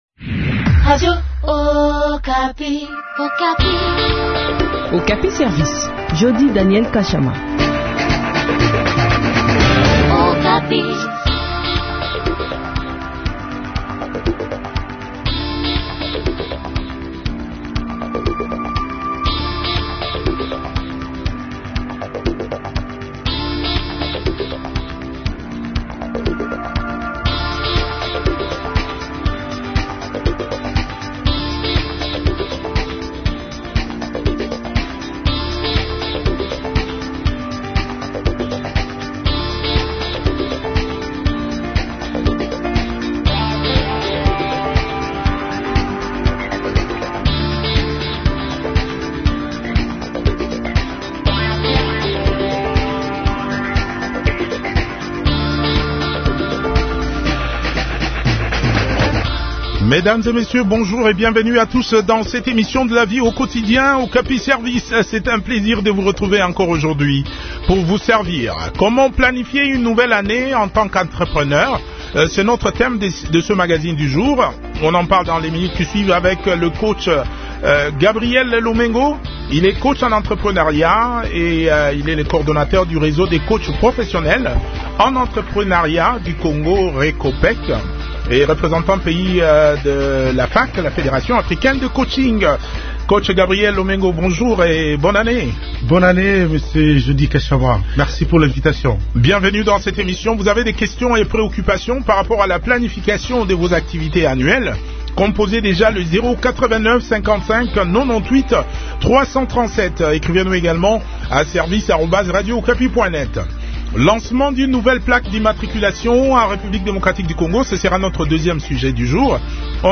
Par ailleurs, cela passe par l’élaboration des documents que sont le plan d’affaires, le manuel des procédures ainsi que la charte de qualité. Suivez l’intégralité de l’entretien